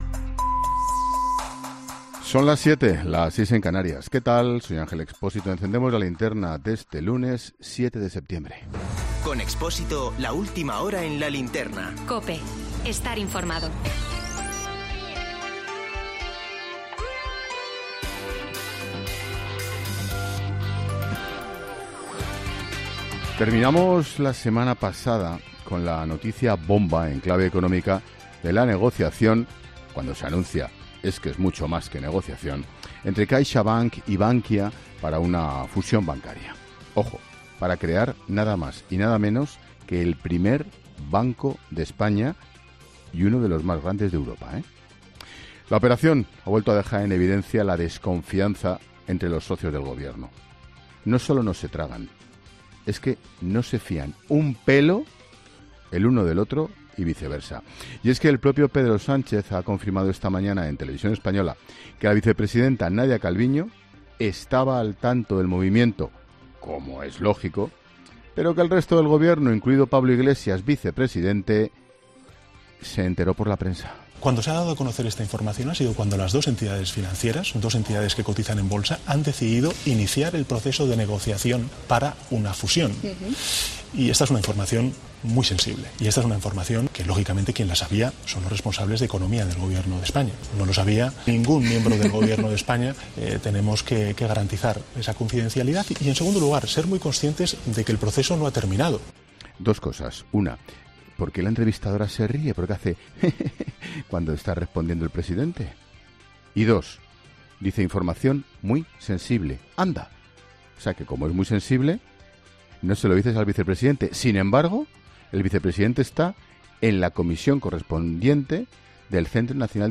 Este lunes, el director de 'La Linterna' ha arrancado su monólogo de las 19h valorando la negociación entre CaixaBank y Bankia para fusionarse.